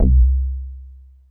Super_BassStation_04(C2).wav